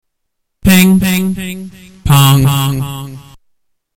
Ping Pong
Category: Animals/Nature   Right: Personal
Tags: Science/Nature The Echo soundboard Echo Echolocation Sound Sound